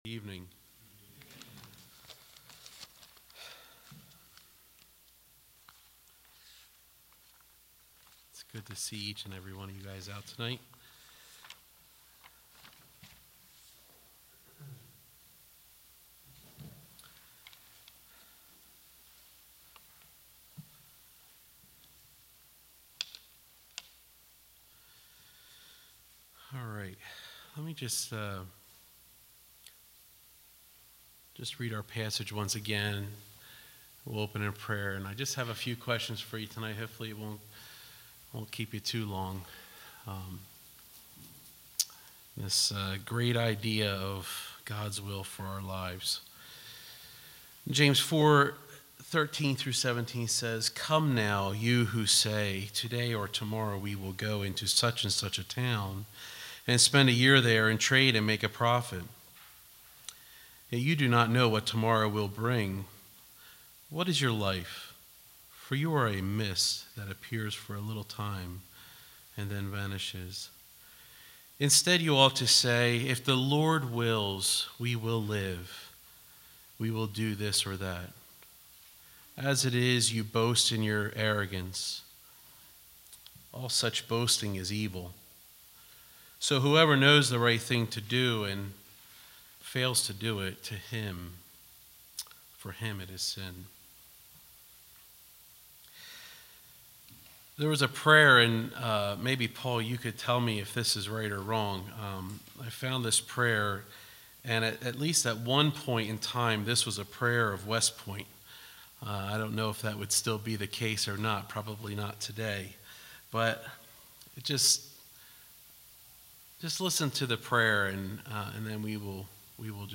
All Sermons Review